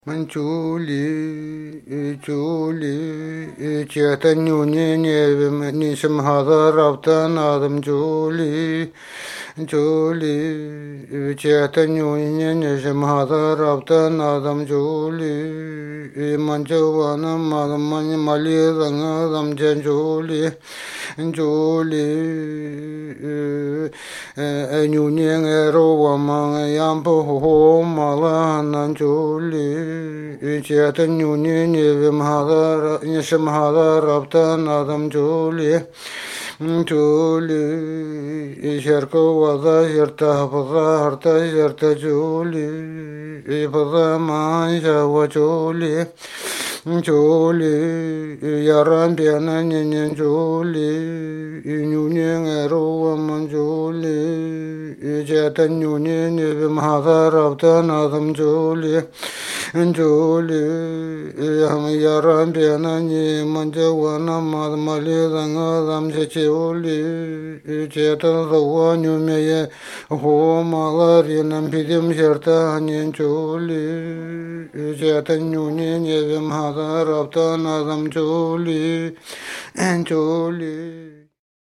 Традиция ненецких песен о животных в поселке Носок Таймырского района Красноярского края
В целом напевы песен-сказок о животных отличаются сравнительной простотой по сравнению с напевами эпических сказаний или «личных» песен.
Важно отметить, что звукоподражания, включенные в текст песни, интонируются певцом в близком певческому регистре и тембре.